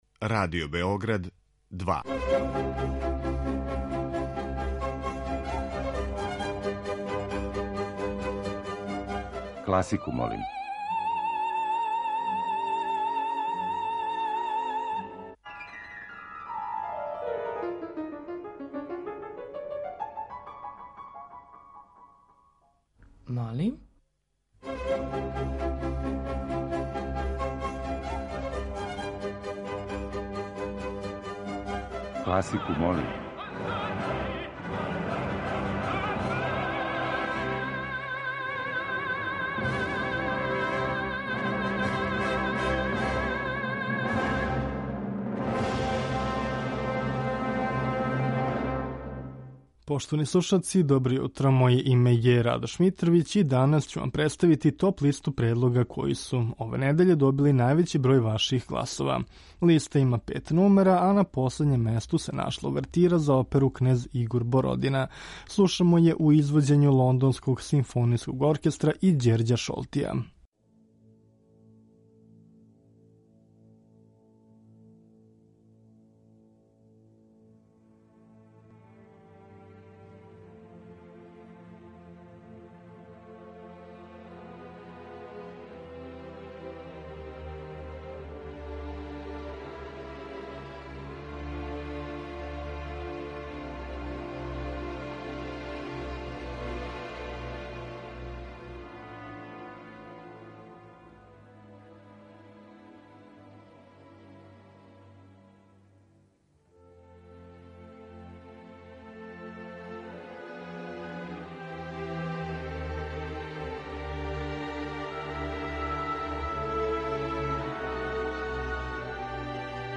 Окосница овонедељне емисије Класику, молим биће оперске увертире.
Поред тога, представићемо Вам и остварења разноликих стилских, жанровских, карактерних и естетичких усмерења.